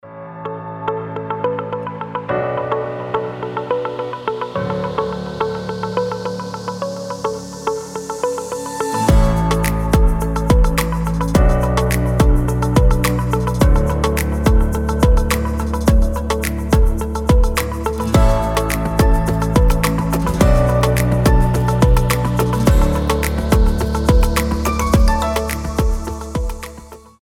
• Качество: 320, Stereo
мелодичные
без слов
вдохновляющие
нежные
Лёгкий и нежный будильник на утро